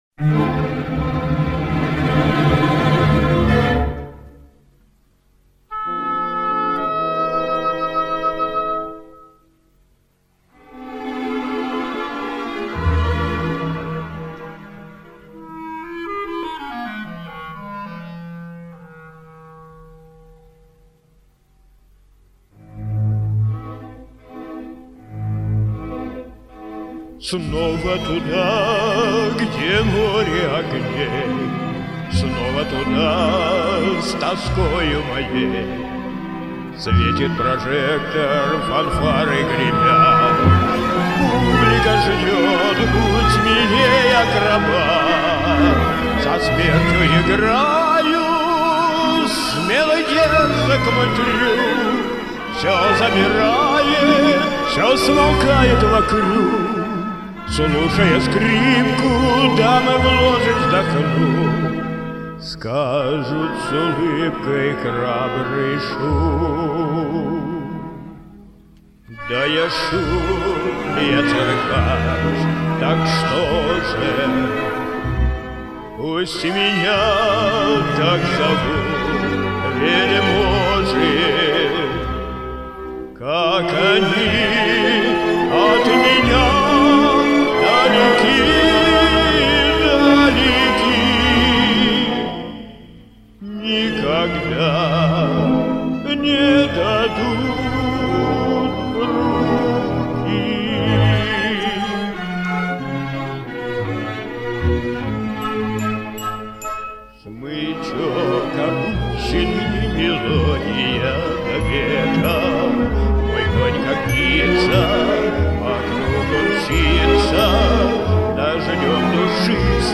Только в одном месте подъезд сбил с толку.